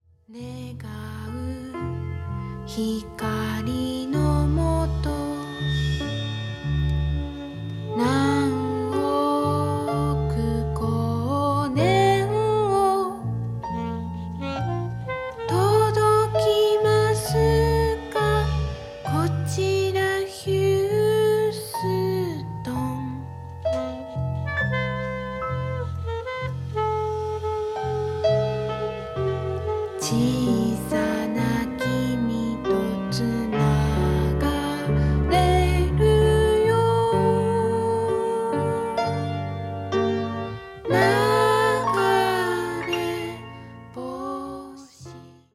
儚くも豊かなメロディーを持つ楽曲
彼らのアングラ的な部分よりも、素朴な中に高い音楽性（と中毒性）が見える、幽玄でメロディアスな好選曲の内容となっています。